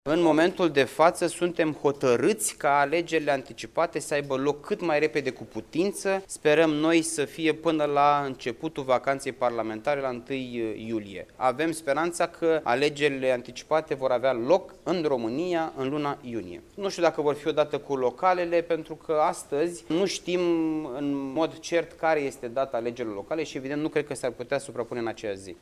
Soluţia alegerilor anticipate este singura variantă agreată de PNL, a susţinut, astăzi,  într-o conferinţă de presă, la Iaşi, ministrul mediului, Costel Alexe.